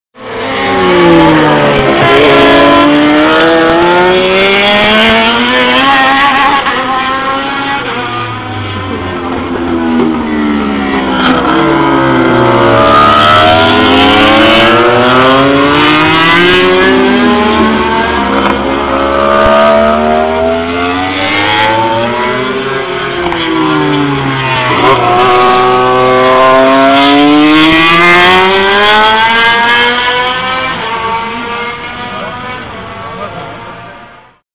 J'en profite pour faire des prises de sons afin d'enregistrer ce bruit rageur que dégagent les MotoGP.
(Lorsqu'on entend le coup de trompe, c'est qu'il s'agit soit de